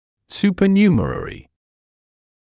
[ˌs(j)uːpə’njuːmərərɪ][ˌс(й)у:пэ’нйу:мэрэри]сверхкомплектный; сверхкомплектный зуб